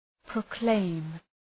Προφορά
{prəʋ’kleım}